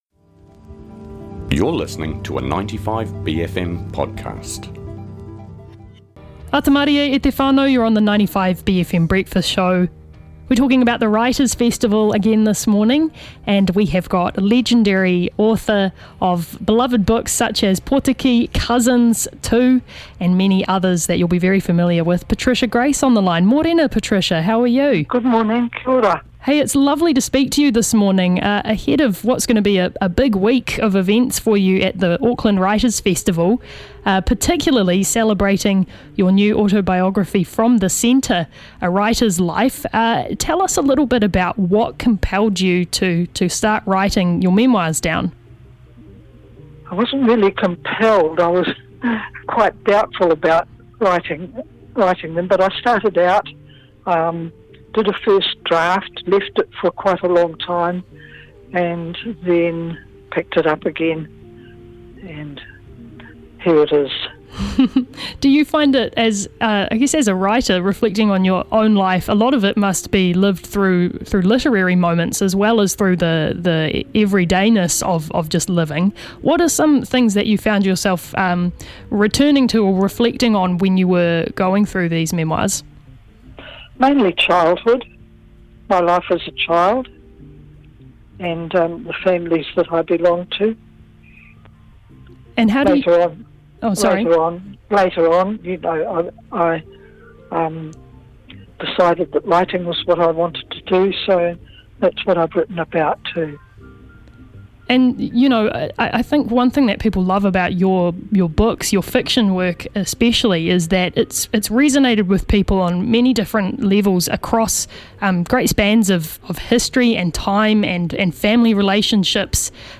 Living legend Patricia Grace (Ngāti Toa, Ngāti Raukawa, Te Ati Awa) phones up to talk to us about her life, legacy, and new memoir where she reveals the experiences that have shaped her world, books and distinctive voice.